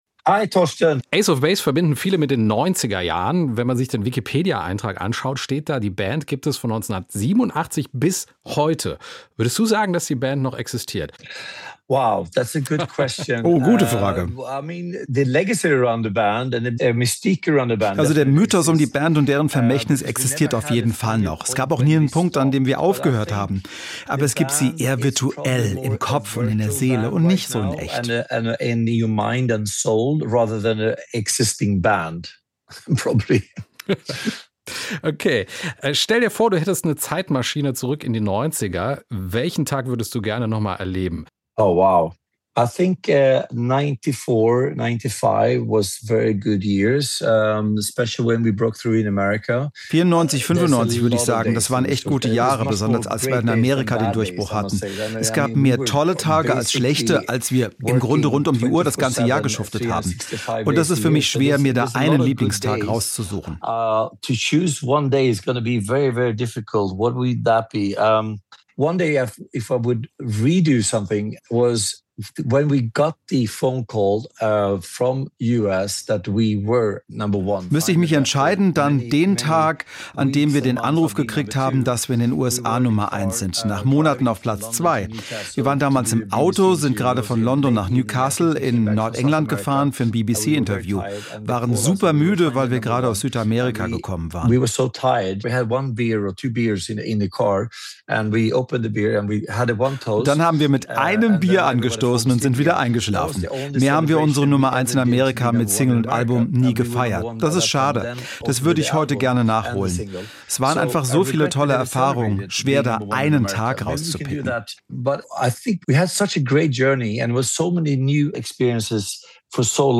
Gründungsmitglied Ulf Ekberg schaut im SWR1 Interview auf Erfolge und Rückschläge der schwedischen Band zurück.
ace-of-base-interview-ulf-ekberg.mp3